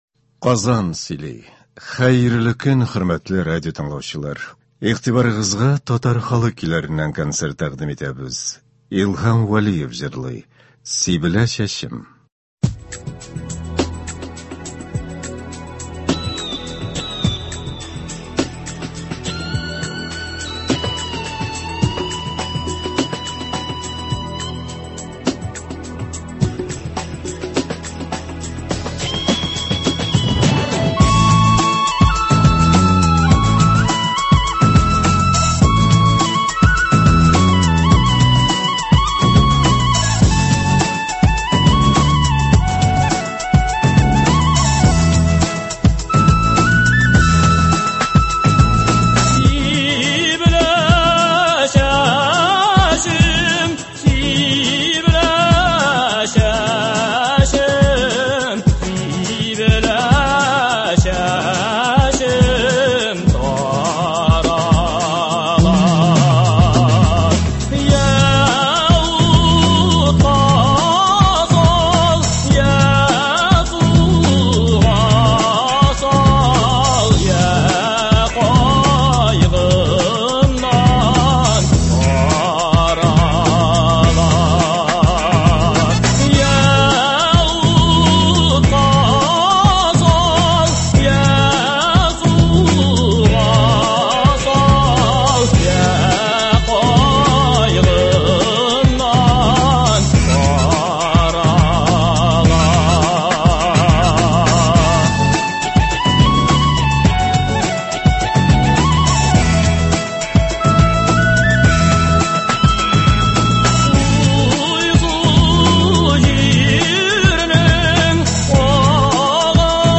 Татар халык көйләре (17.07.21)
Бүген без сезнең игътибарга радио фондында сакланган җырлардан төзелгән концерт тыңларга тәкъдим итәбез.